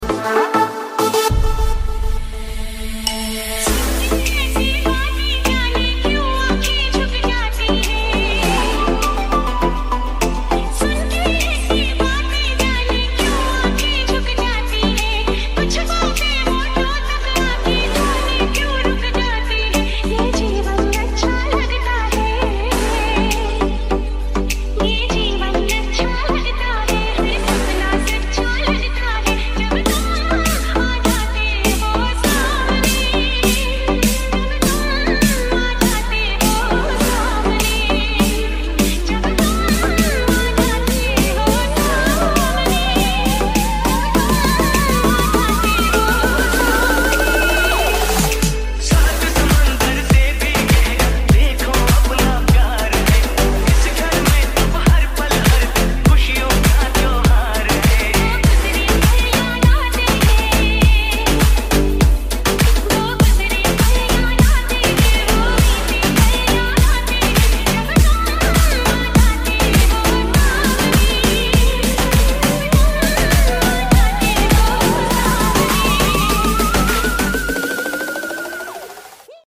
Golden Hit Remix